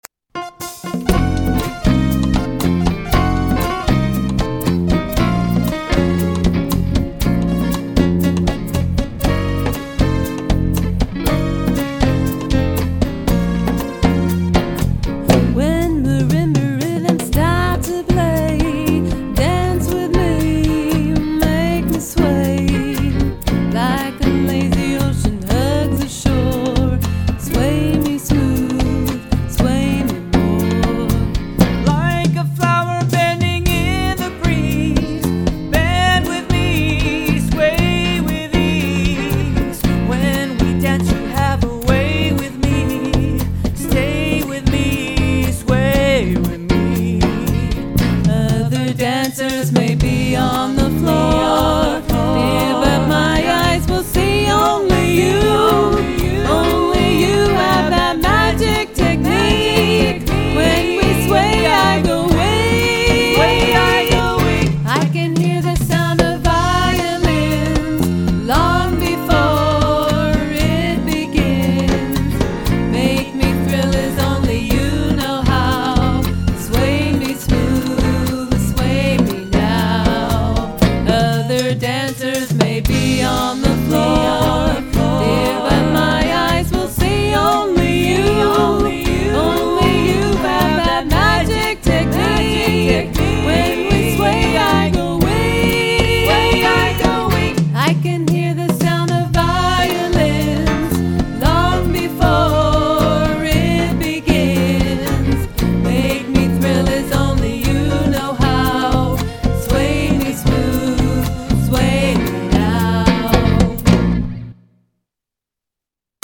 Recorded at Polar Productions Studio, Pismo Beach, CA